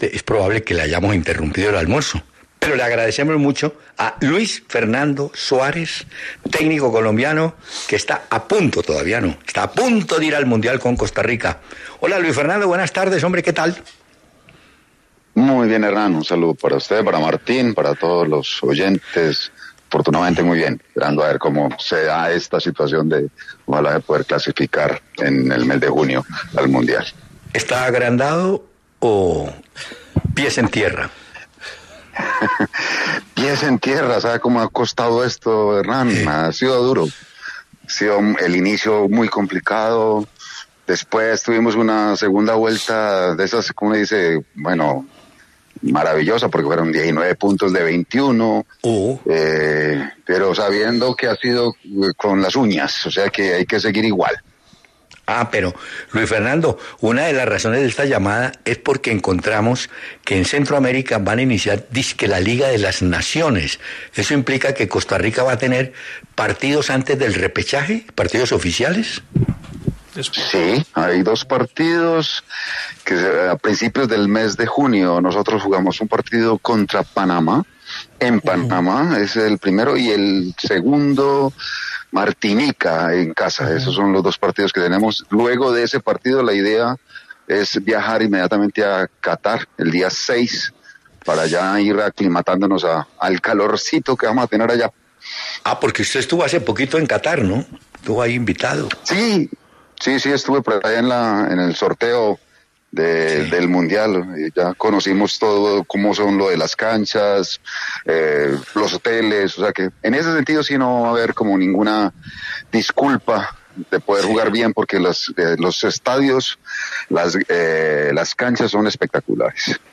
Luis Fernando Suárez estuvo con Peláez y de Francisco en La W, donde dio detalles de su proceso con la selección de Costa Rica y recordó cuando fue dirigido por Francisco Maturana